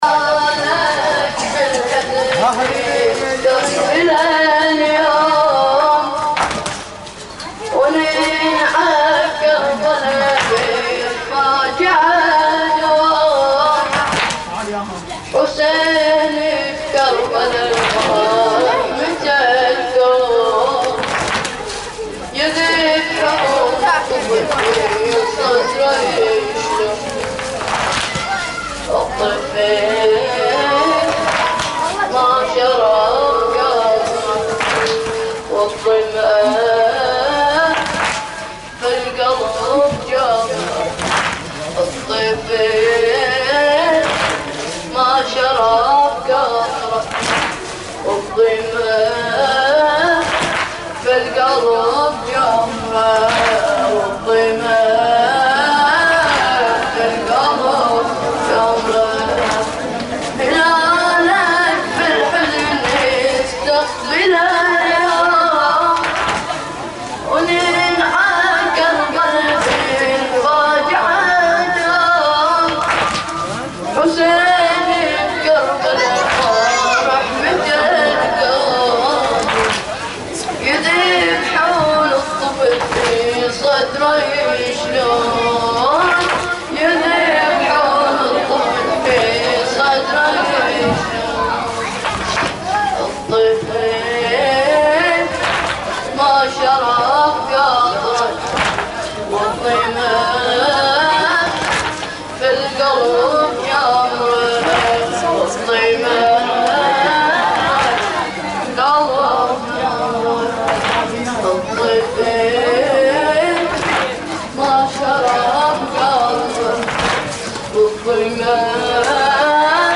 تغطية صوتية: ليلة حادي محرم 1438هـ في المأتم